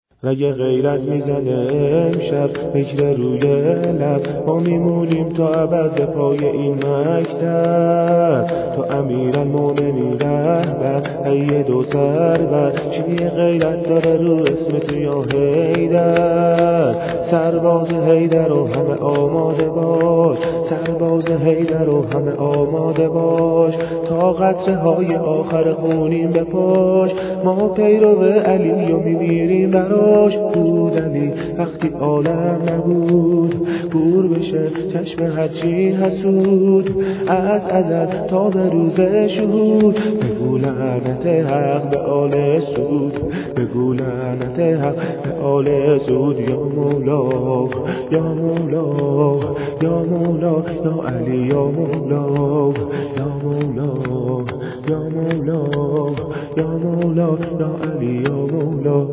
شور ، سرود